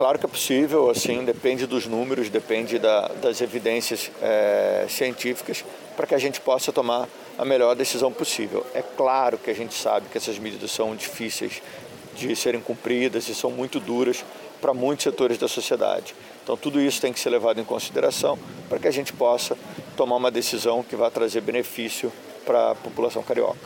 Durante entrevista, o Secretário afirmou ainda que a variante de Manaus é responsável por mais de 80% das infecções pelo Coronavirus na cidade do Rio de Janeiro.